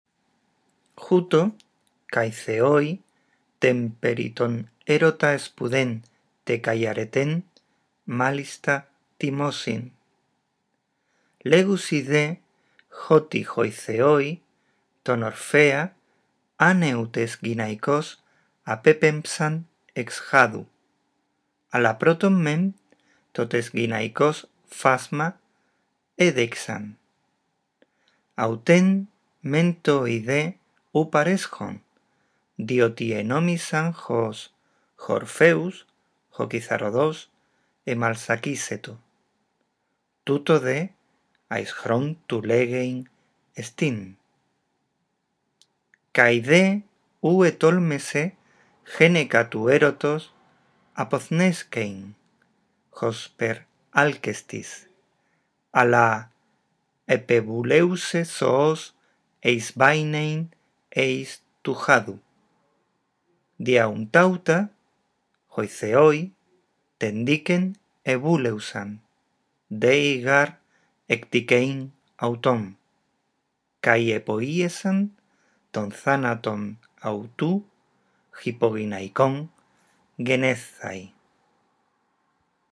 Leer el texto en voz alta; este archivo de audio te ayudará a cuidar la pronunciación y la entonación.